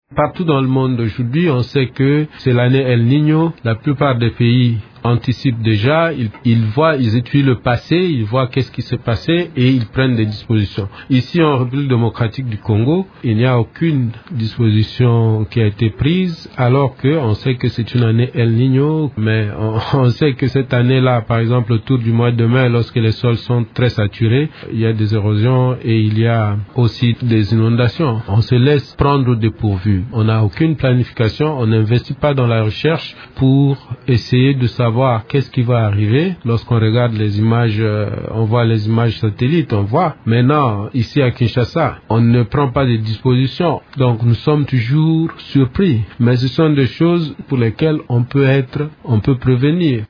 Vous pouvez écouter ses explications dans cet extrait sonore: